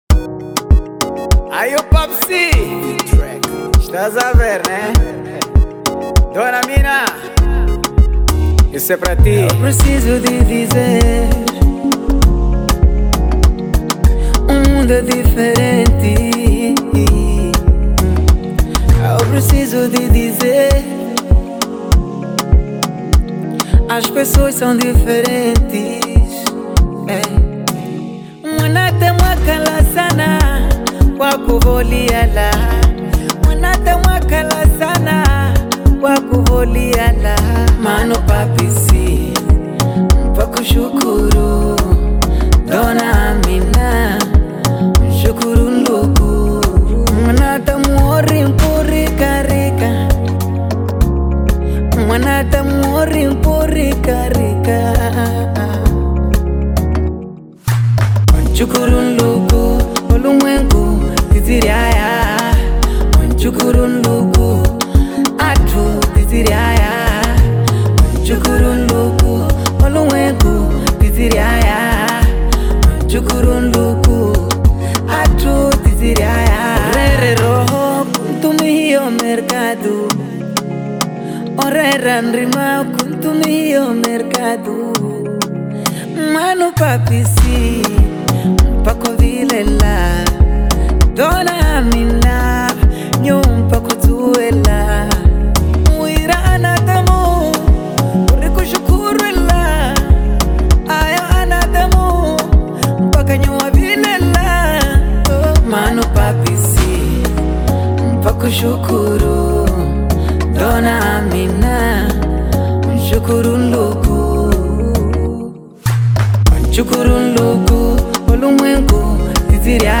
Genero: Afrobeat